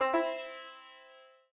sitar_ce.ogg